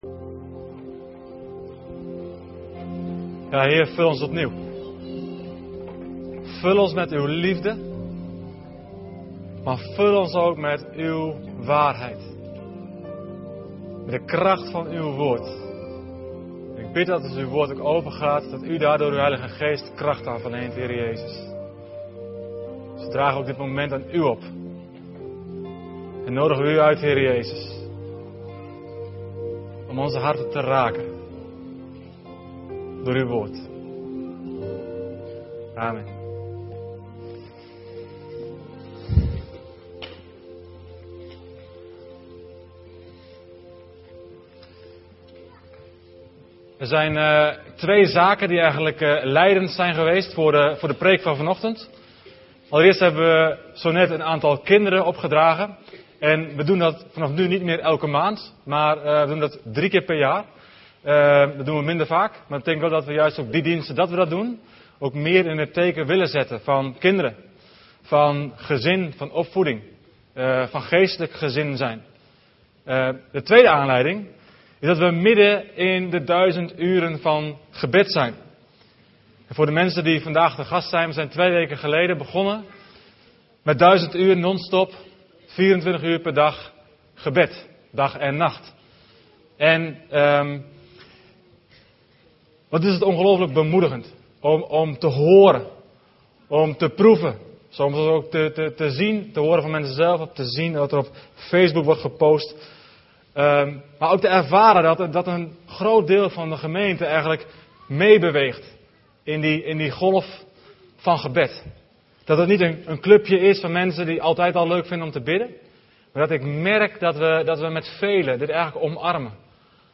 Om ook de woordverkondiging van de zondagse diensten te kunnen beluisteren op welk moment en waar je maar wilt, worden de preken ook als audio-uitzending gedeeld.